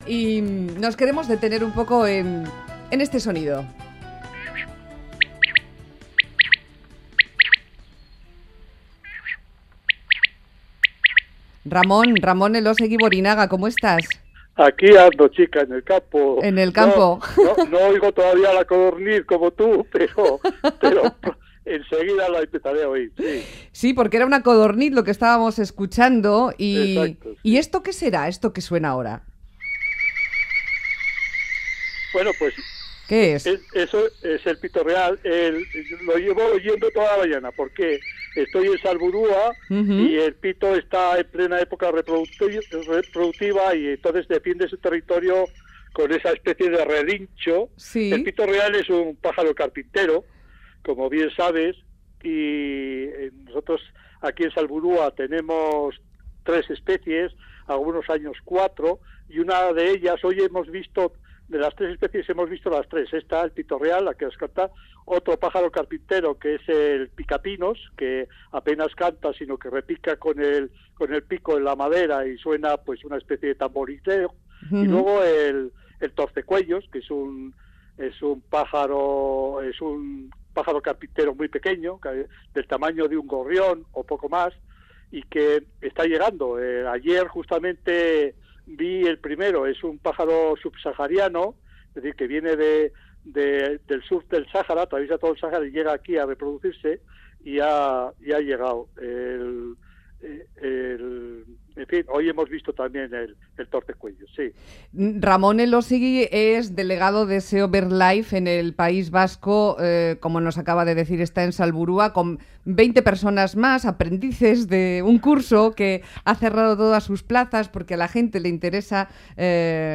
Aprender a distinguir cantos de aves y pájaros | Radio Euskadi
Junto a él, en Salburua (junto a Vitoria), 20 alumnos tratan de aprender qué es ese pájaro que canta. Y hablamos de diferentes pájaros de nuestro entorno.